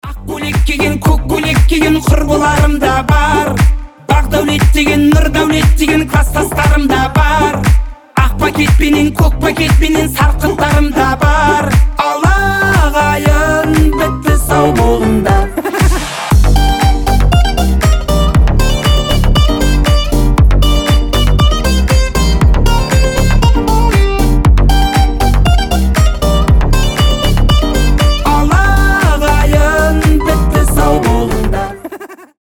• Качество: 320, Stereo
веселые
казахские